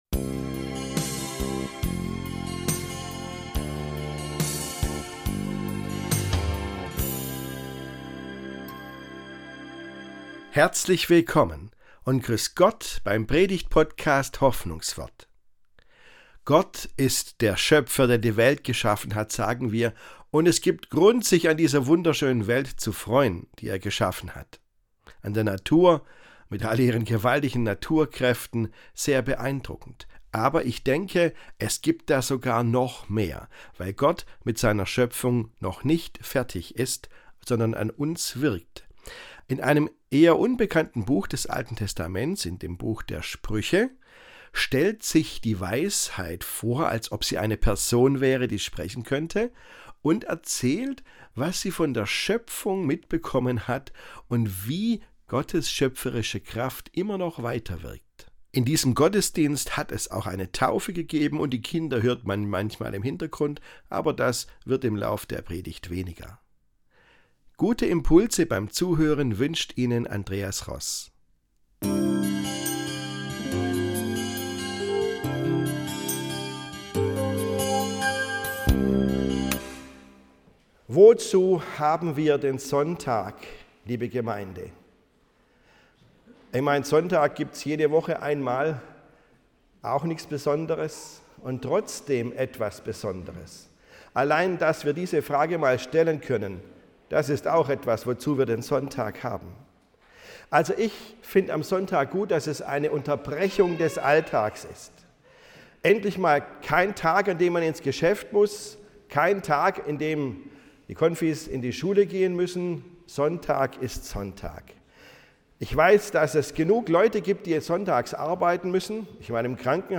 Stimmt es, dass Gott die Welt geschaffen hat und sich seitdem aus dem Weltenlauf raushält? Diese Predigt zeigt eine andere Richtung auf: Wir haben Grund, uns an Gott und am Leben zu freuen, weil er auch an uns mit seiner schöpferischen Kraft wirkt.